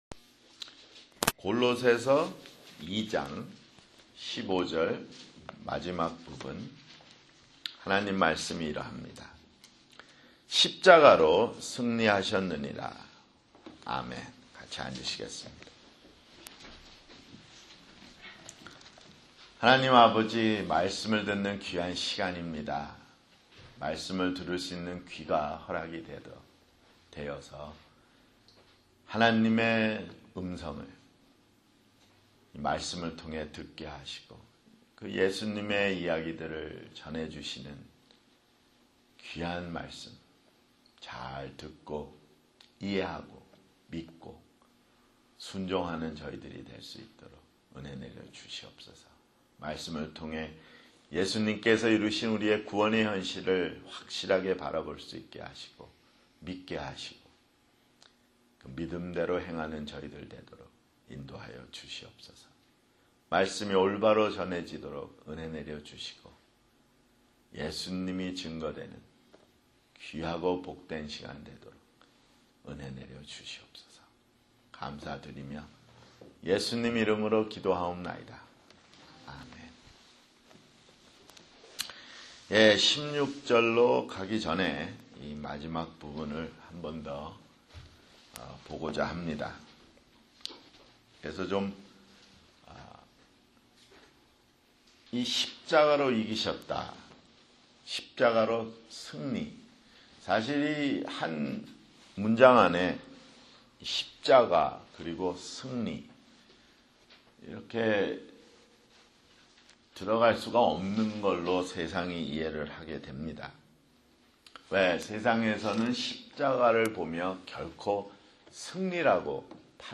[주일설교] 골로새서 (49)